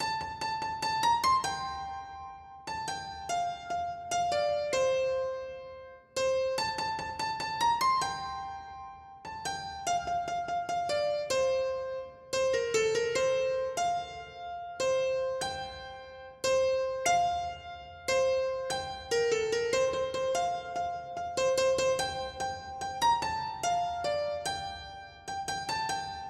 原声钢琴146bpm Fmajor Hi
描述：F大调原声钢琴循环曲。
Tag: 146 bpm Pop Loops Piano Loops 4.42 MB wav Key : F